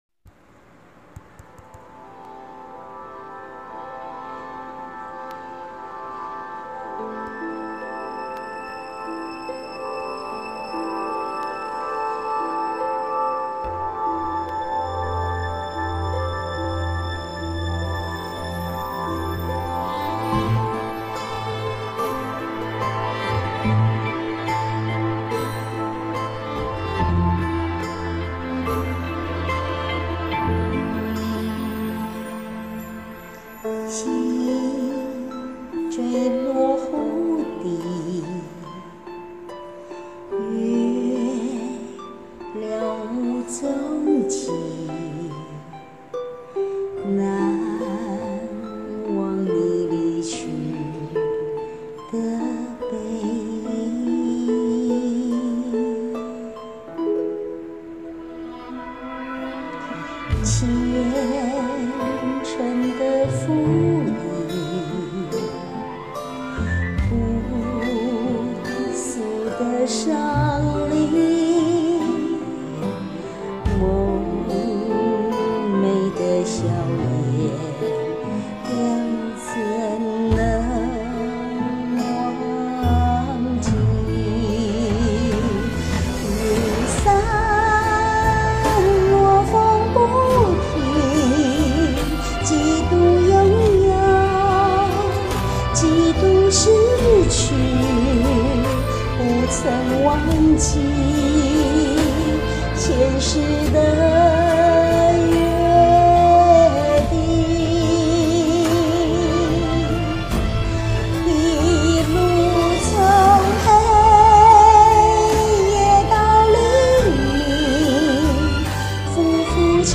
雖說女生要唱這樣的歌
(真不是普通的難唱.....歐巴桑差點燒聲啊........)